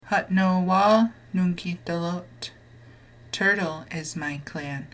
Click below each question and answer to hear the Oneida and English pronunciations.